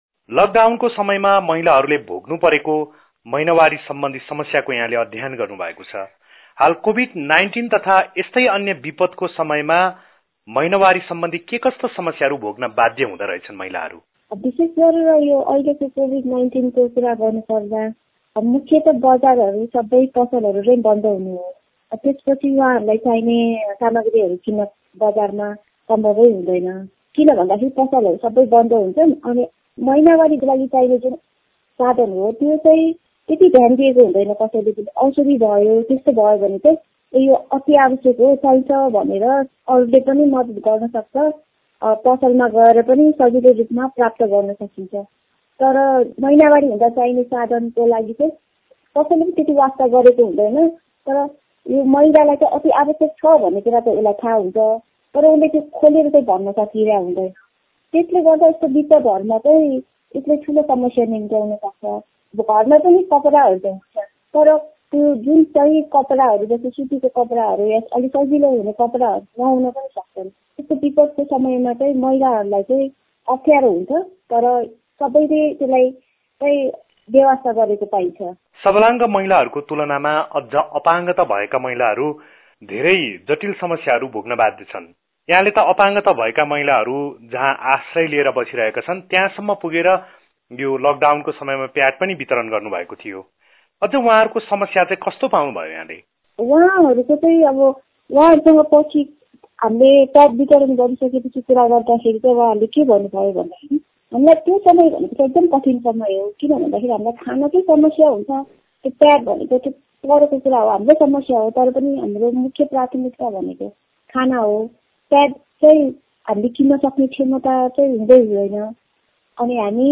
कुराकानी गरेका छौं ।